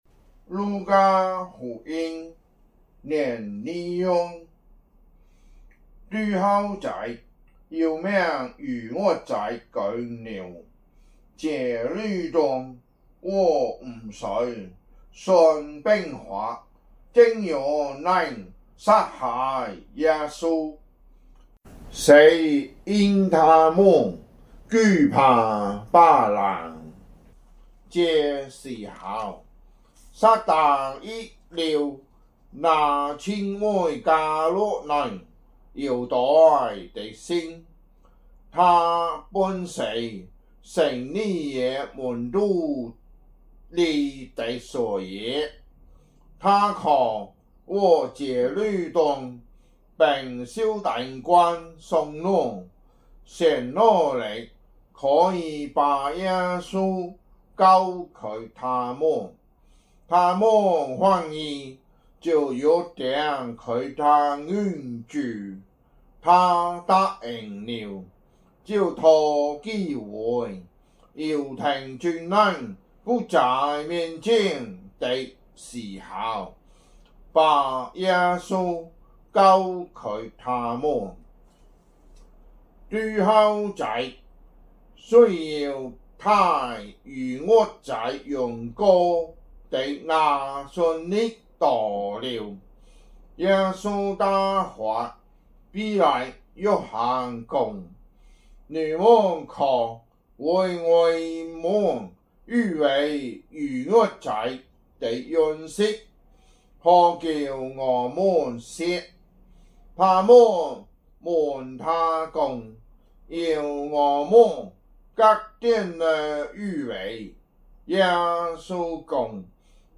福州話有聲聖經 路加福音 22章